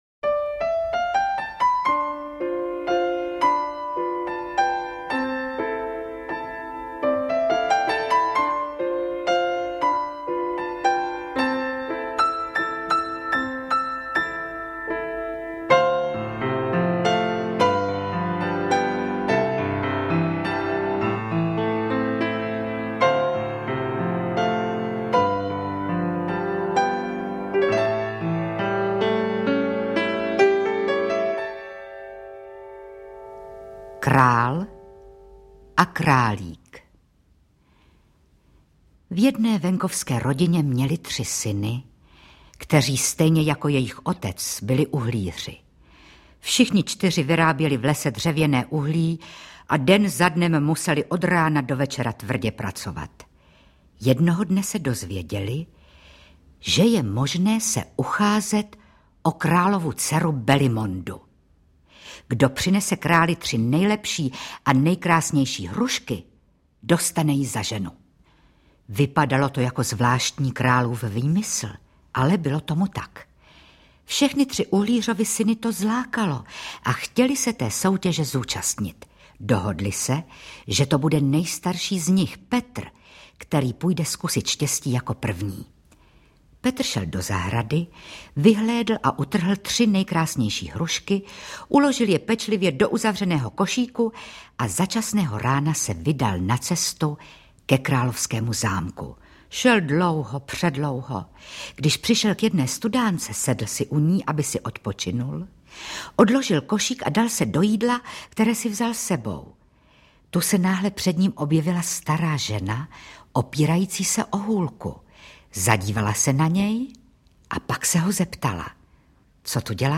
Interpret:  Zdenka Hadrbolcová
AudioKniha ke stažení, 4 x mp3, délka 58 min., velikost 53,4 MB, česky